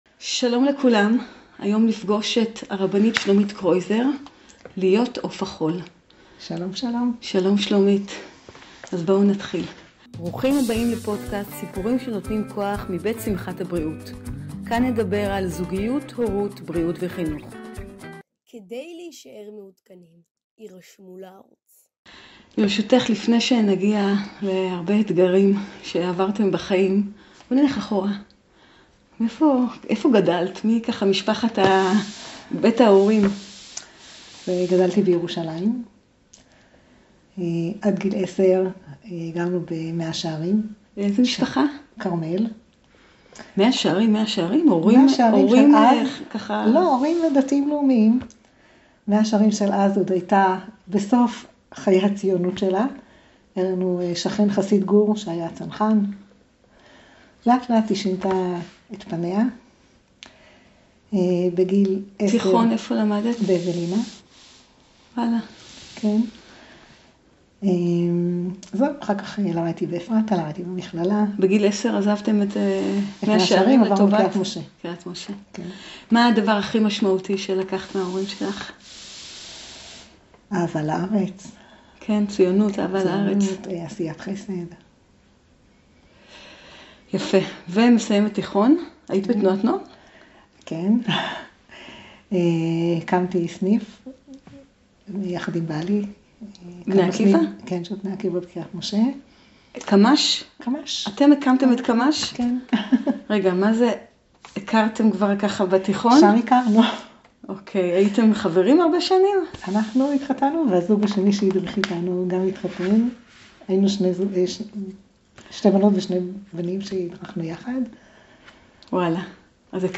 שיעורים באמונה - אמונה בשעת משבר